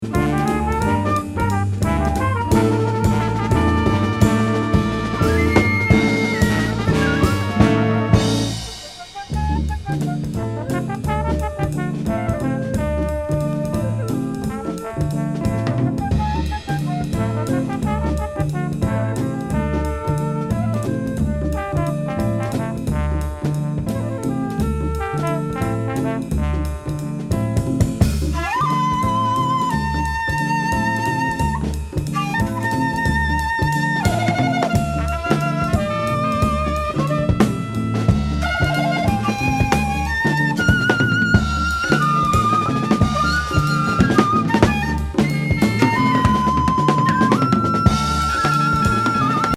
マスター・サウンドで音質バッチリ!!帯付。